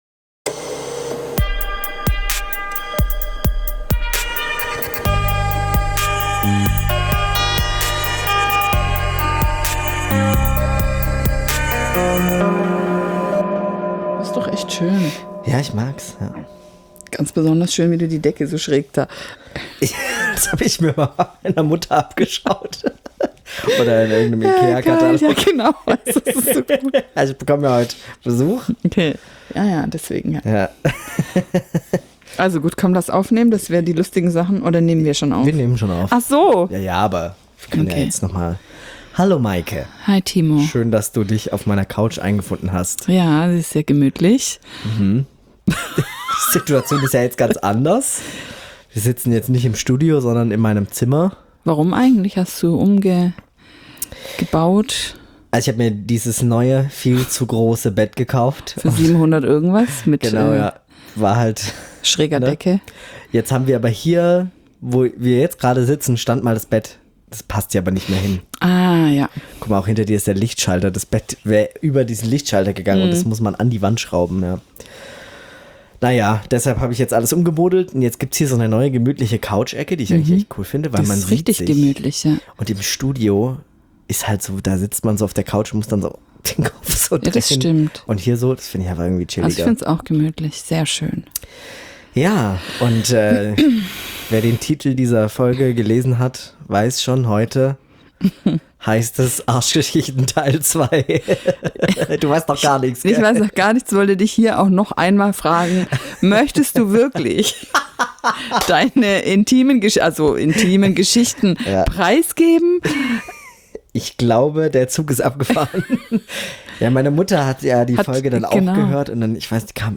Eine Krankenschwester mit 15 Jahren Berufserfahrung und ein Notfallsanitäter-Azubi, der gerade im ersten Jahr seiner Ausbildung ist unterhalten sich über die schrägsten, lustigsten und ekligsten Geschichten aus dem Alltag des medizinischen Fachpersonals.